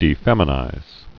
(dē-fĕmə-nīz)